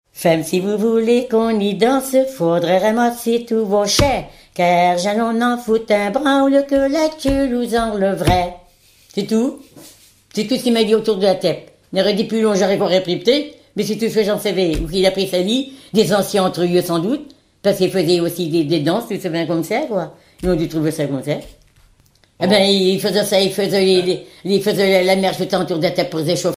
Couplets à danser
branle : avant-deux
Chanteuse du pays de Redon